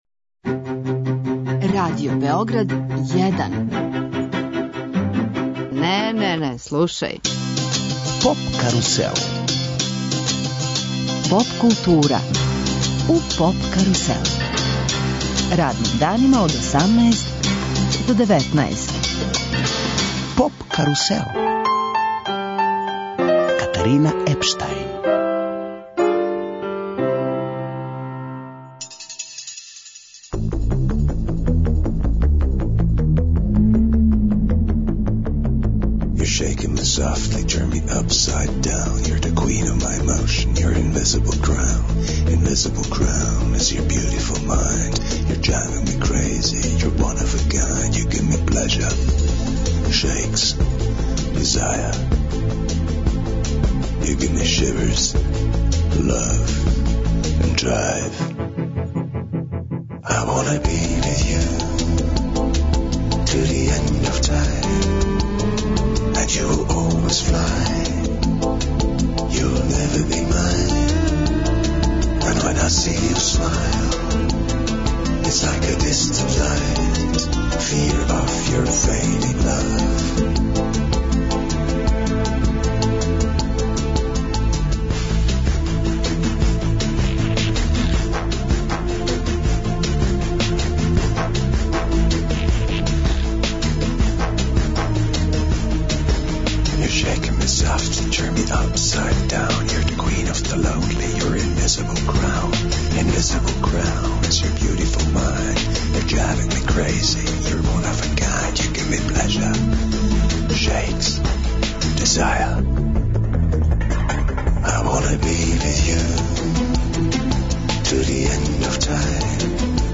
Слушаћемо музику коју је стварао прошле године и причаћемо о положају наших уметника ван Србије.